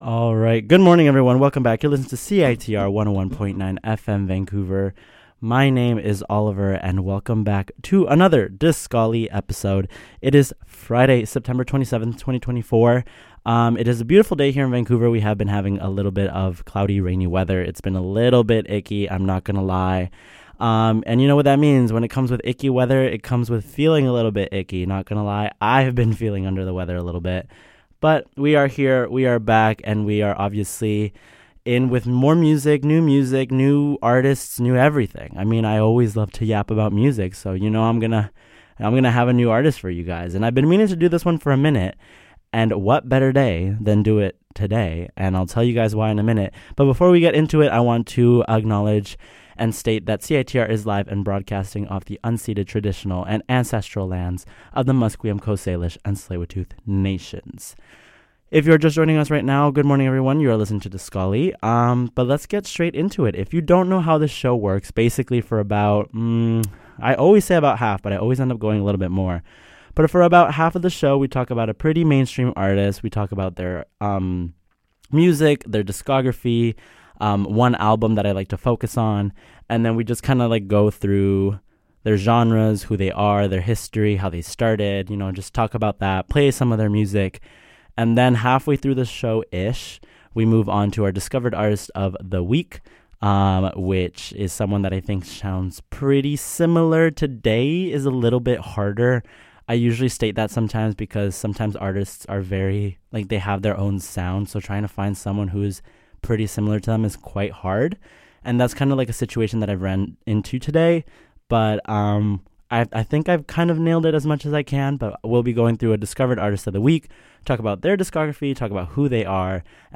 X's marks the spot if you're looking to discover the wonders and comfort of dream pop, slowcore, and psychedelic pop music.